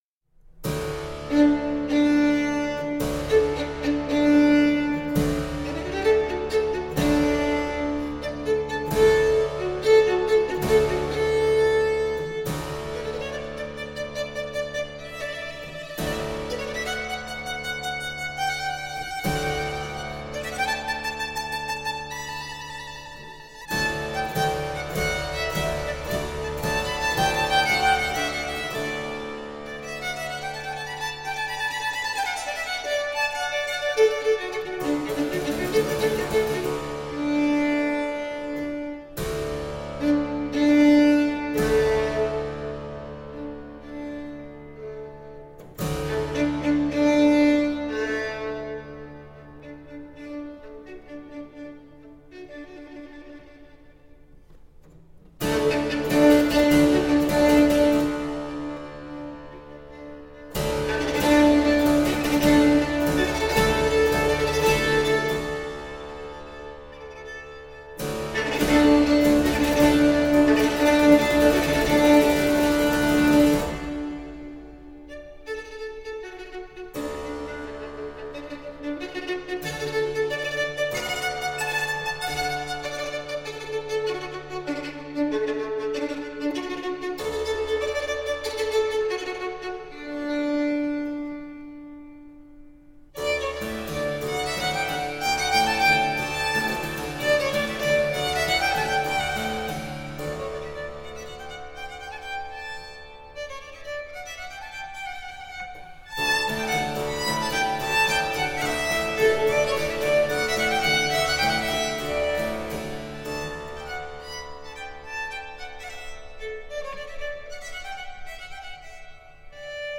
17th century baroque ensemble.
Classical, Orchestral, Baroque